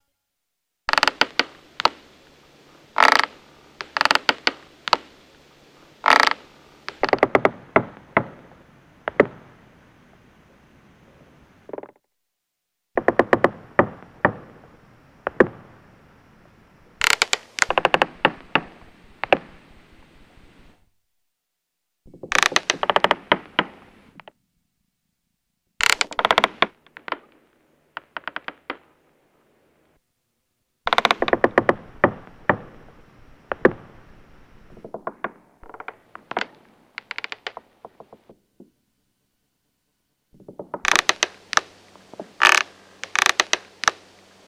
Rope Creaks Louder